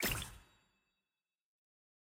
sfx-jfe-ui-roomselect-room-stars.ogg